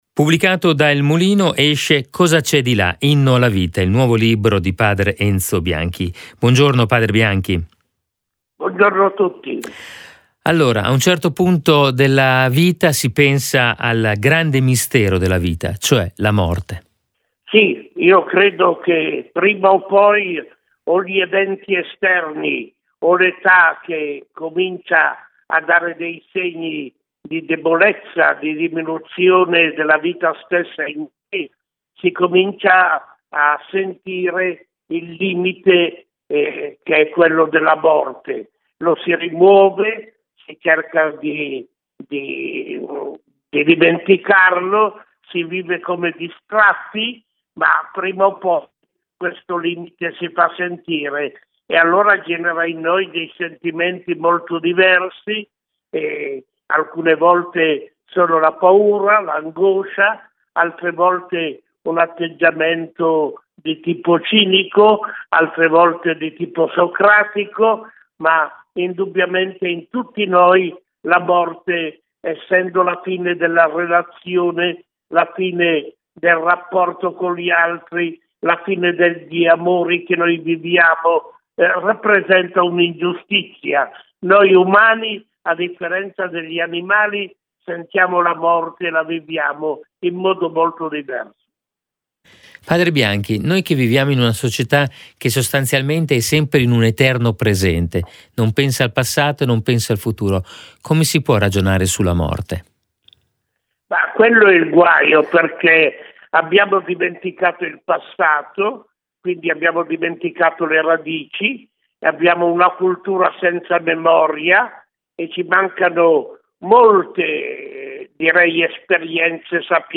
Home Magazine Interviste Anteprima a Bologna del nuovo libro di Padre Enzo Bianchi “Cosa c’è...